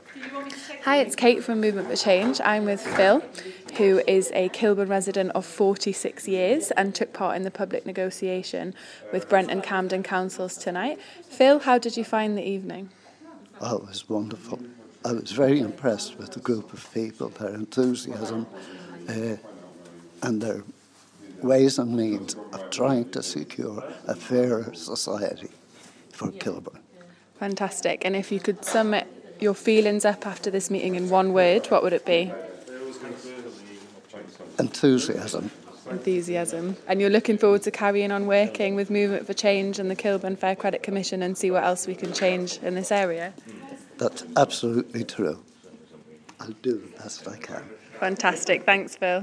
the Kilburn public negotiation